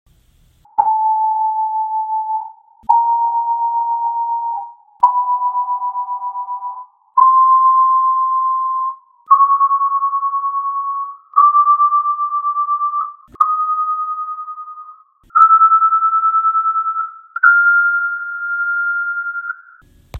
This is a free analysis generated by a recording of M3’s and P4’s submitted to me by one of my subscribers.
Goal: Chromatically increasing by 0% to 12%
M3, filtered:
2. Listen to the filtered recordings and try to confirm aurally that the beat speeds are following what the graph says.